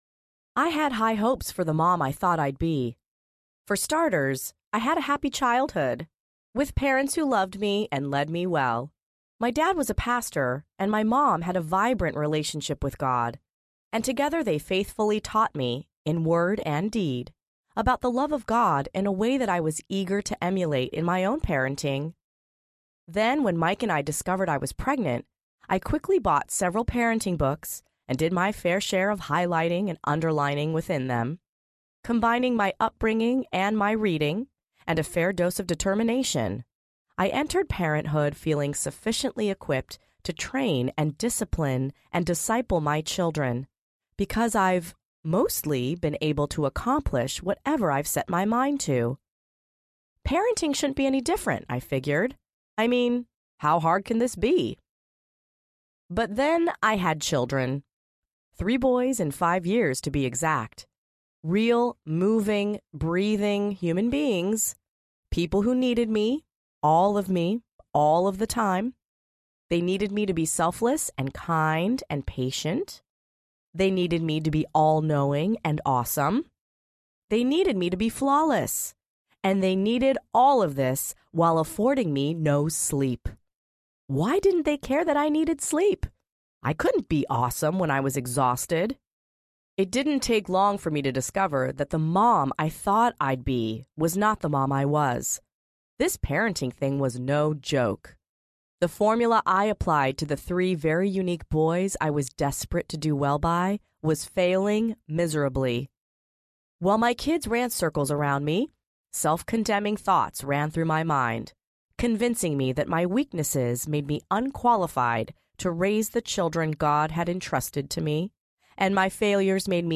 Mom Set Free Audiobook
Narrator
6.5 Hrs. – Unabridged